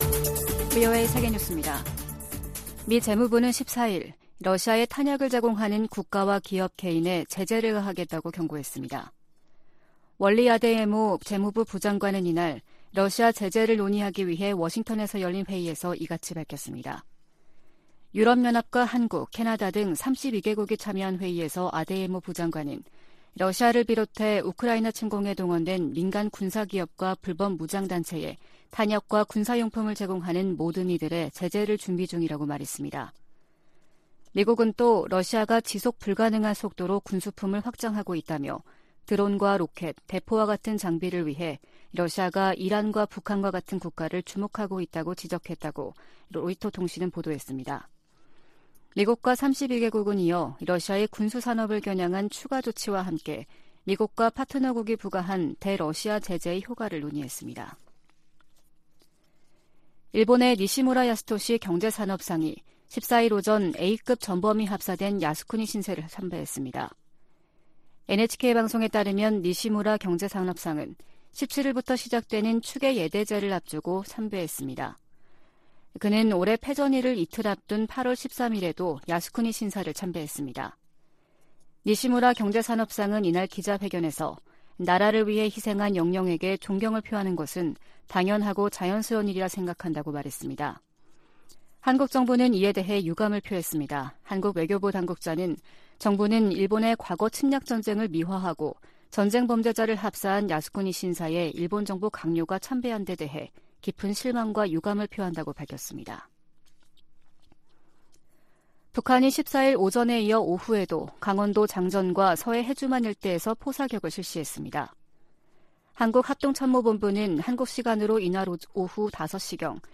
VOA 한국어 아침 뉴스 프로그램 '워싱턴 뉴스 광장' 2022년 10월 15일 방송입니다. 북한이 포 사격을 포함해 군용기 위협 비행, 탄도미사일 발사 등 무차별 심야 도발을 벌였습니다. 한국 정부가 북한의 노골화되는 전술핵 위협에 대응해, 5년 만에 대북 독자 제재에 나섰습니다. 미국이 로널드 레이건 항모강습단을 동원해 한국·일본과 실시한 연합훈련은 역내 안정 도전 세력에 대한 단합된 결의를 보여준다고 미 7함대가 밝혔습니다.